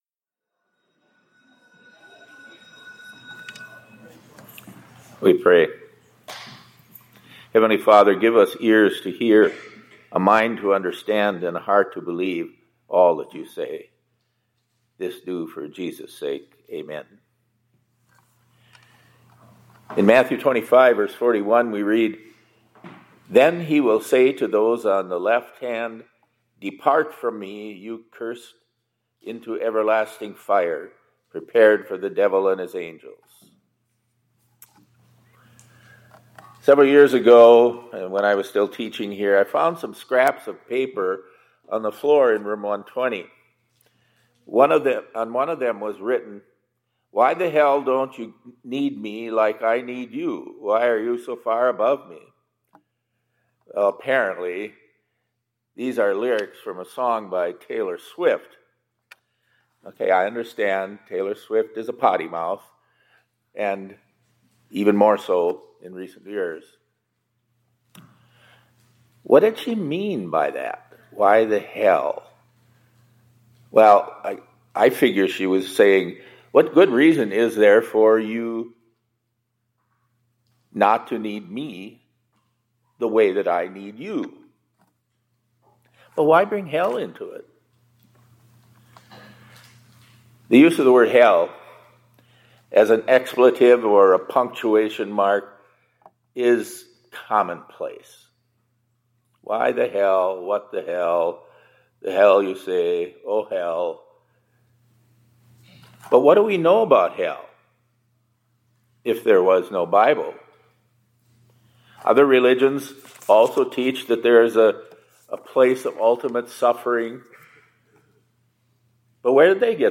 2026-03-10 ILC Chapel — It’s Important to Keep Hell Real